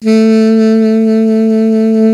55af-sax03-A2.aif